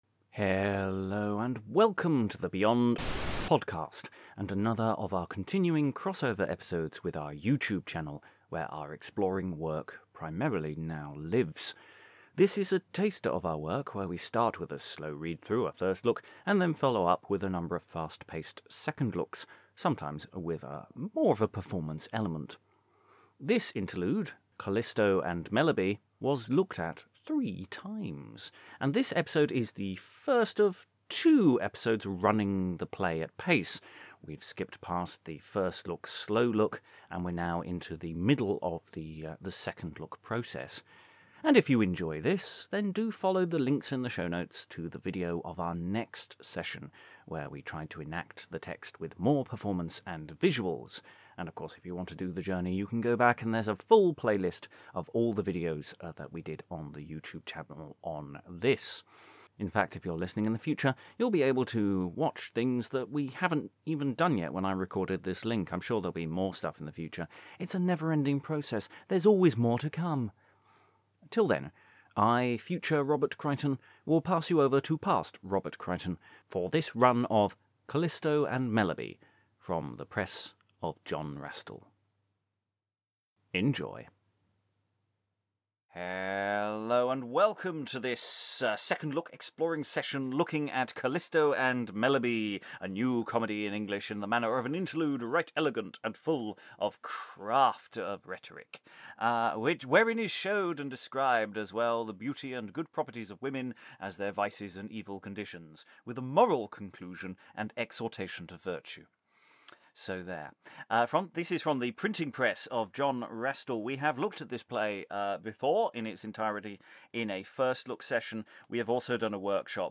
A read through and discussion of Calisto and Melebea, from the press of John Rastell